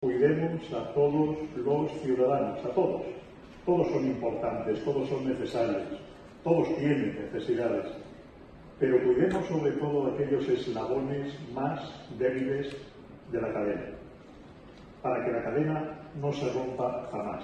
Foto de familia de los premiados junto a las autoridadesLa semana siguiente, el jueves 24 de octubre, fue la ONCE de Cantabria la que rindió homenaje al espíritu solidario de la sociedad montañesa con la entrega de sus galardones autonómicos 2019, en una gala celebrada en un abarrotado Teatro Casyc de Santander y presidida por los máximos responsables de la Comunidad Autónoma, Miguel Ángel Revilla, y del Grupo Social ONCE, Miguel Carballeda.